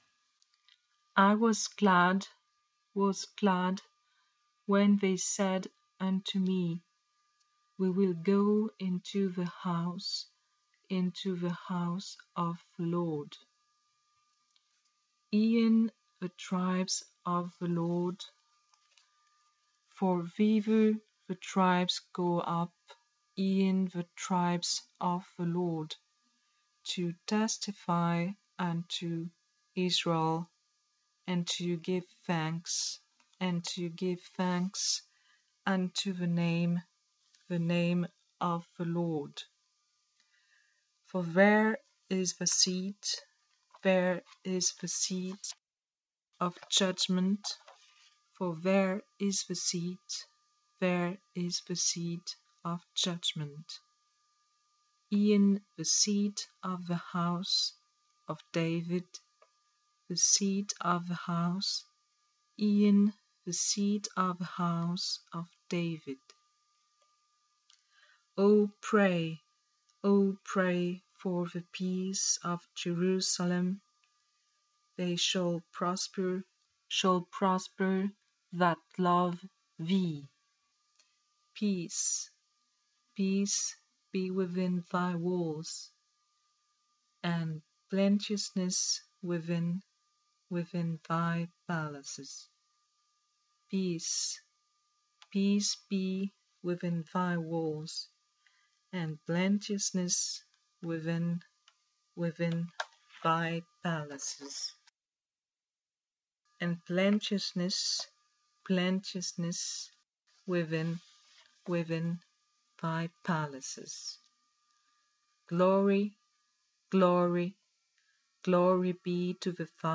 Prononciation de I was glad :
Lecture_Purcell_I_was_glad.mp3